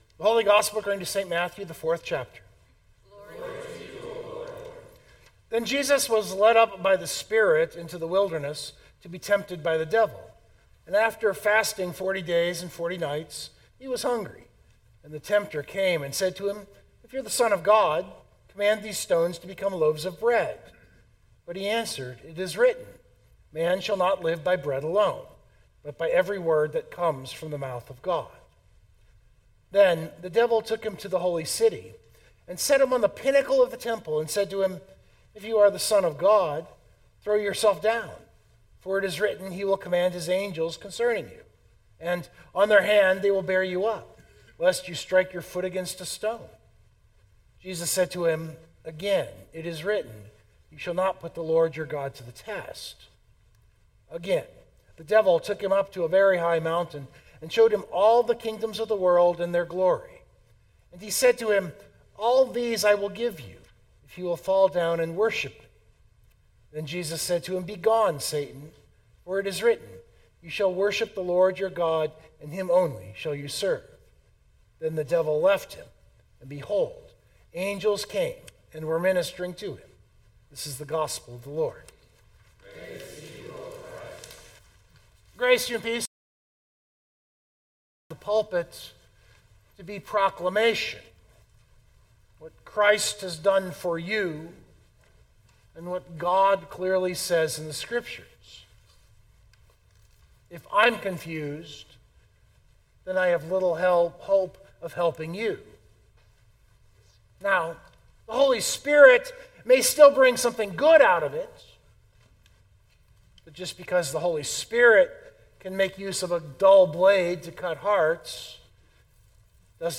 022226 Sermon Download Biblical Text: Matthew 4:1-11 I start this sermon with a little reflection on what I believe the purpose of preaching is – proclamation.